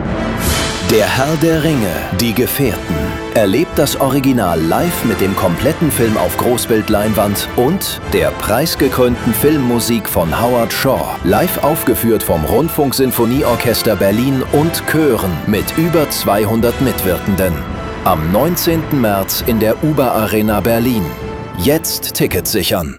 Das Rundfunk-Sinfonieorchester Berlin, der Große Chor des Collegium Musicum Berlin sowie der Kinderchor der Deutschen Oper Berlin sorgen gemeinsam für ein unvergessliches Konzert-Erlebnis – begleitet von einer beeindruckenden Großbild-Projektion.